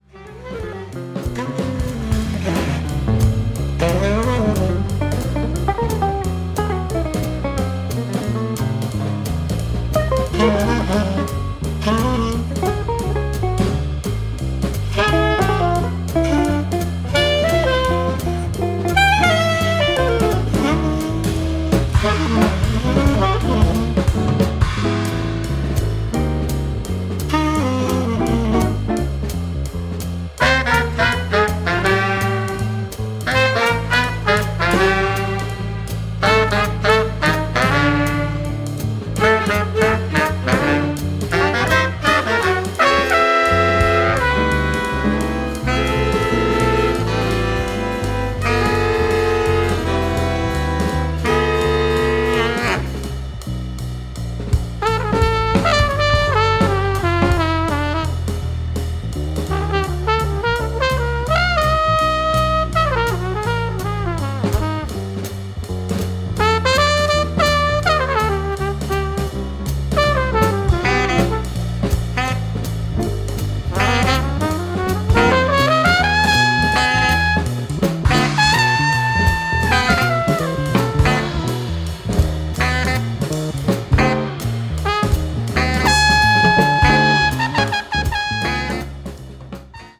全編通して温かく豊かな味わいに満ちた仕上がりとなっています。